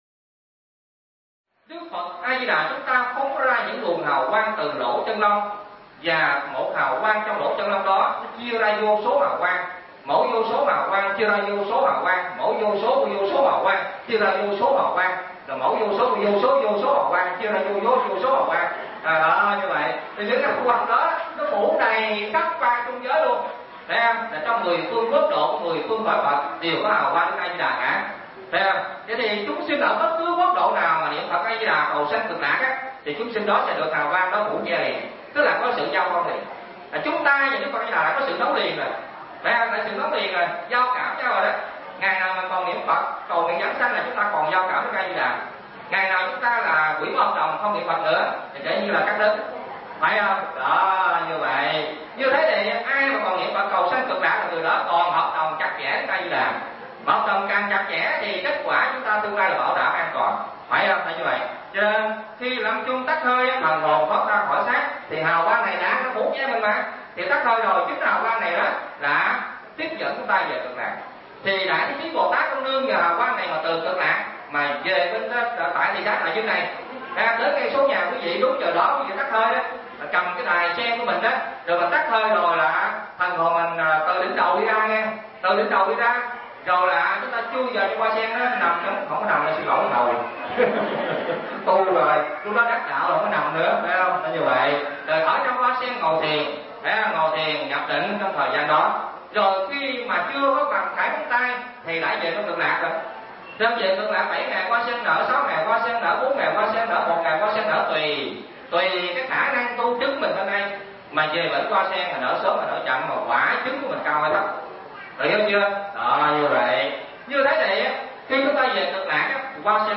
Tải mp3 Pháp Thoại Vài Nét Về Giáo Lý Đạo Phật Phần 2